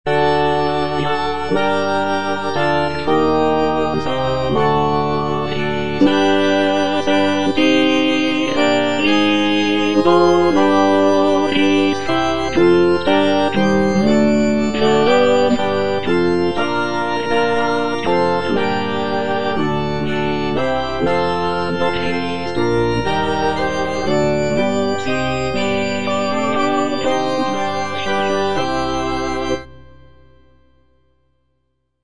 G.P. DA PALESTRINA - STABAT MATER Eja Mater, fons amoris (tenor I) (Emphasised voice and other voices) Ads stop: auto-stop
a sacred choral work
Composed in the late 16th century, Palestrina's setting of the Stabat Mater is known for its emotional depth, intricate polyphonic textures, and expressive harmonies.